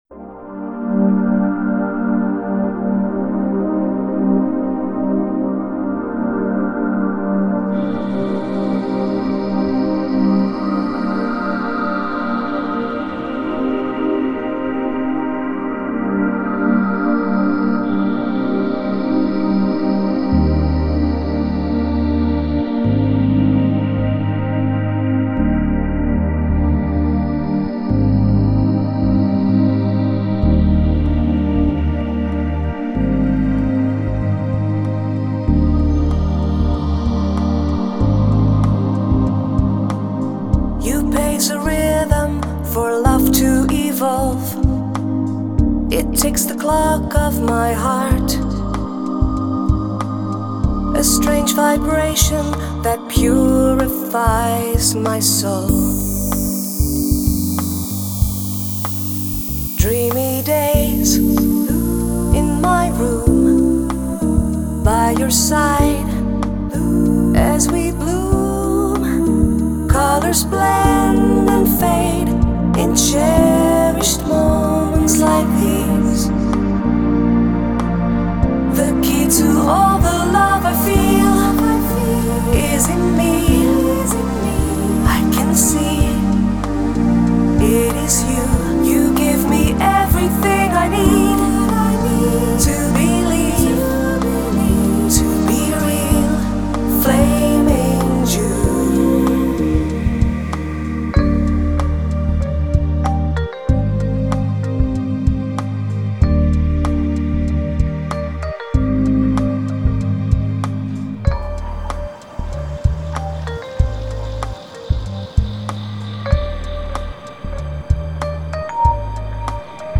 Genre : Electronic